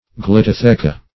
Search Result for " glyptotheca" : The Collaborative International Dictionary of English v.0.48: Glyptotheca \Glyp`to*the"ca\, n. [NL., fr. Gr.